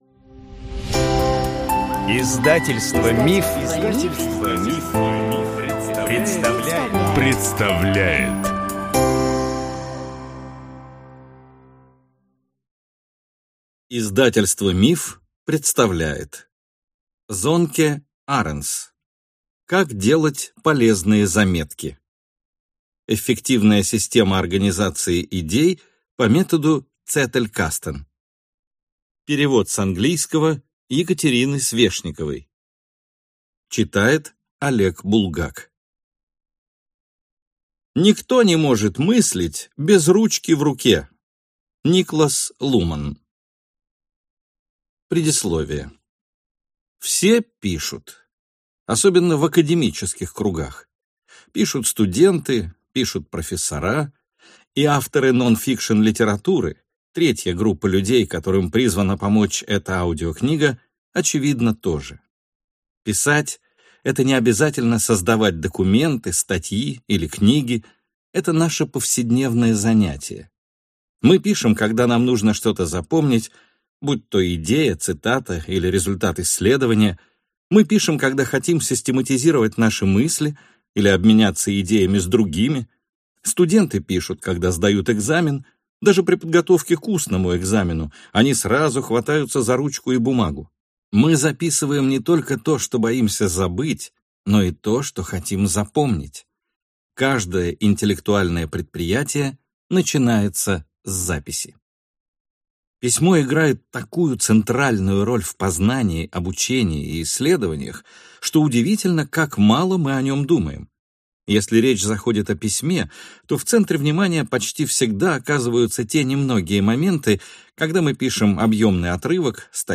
Аудиокнига Как делать полезные заметки. Эффективная система организации идей по методу Zettelkasten | Библиотека аудиокниг